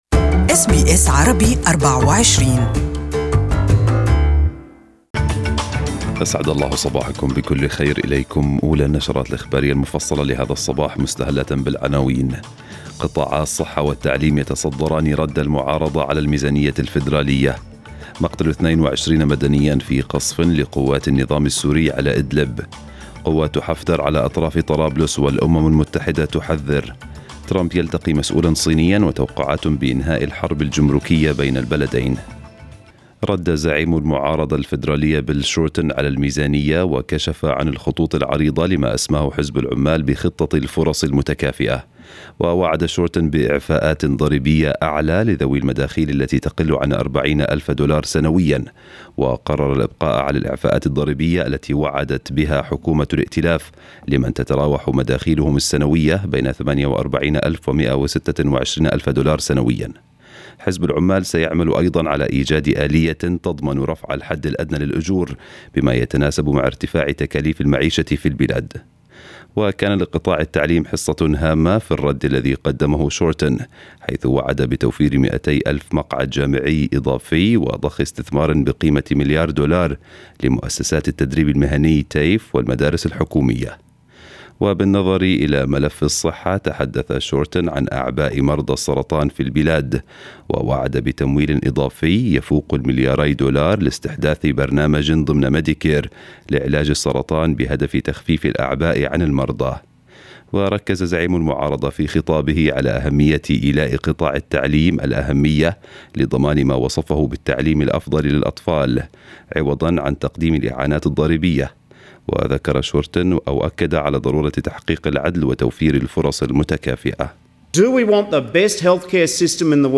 News Bulletin in Arabic for the day